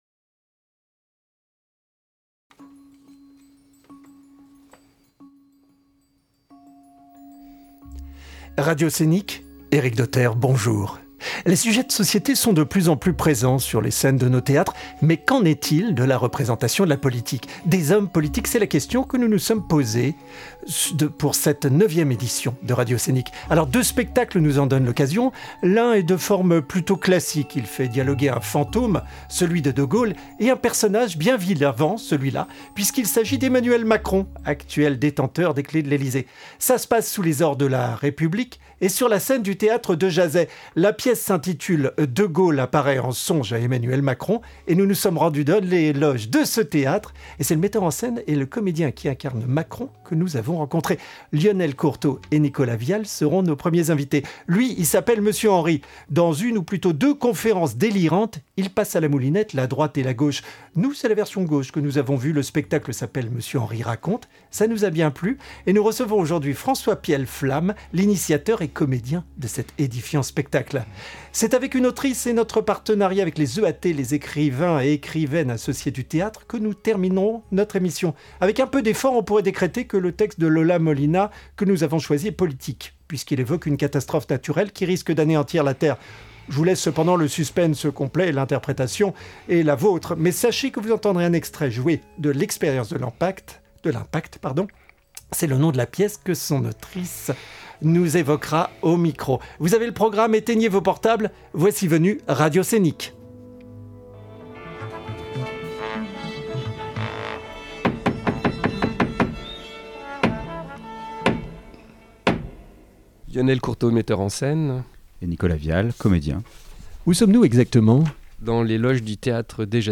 "La politique sur scène". Nos invités :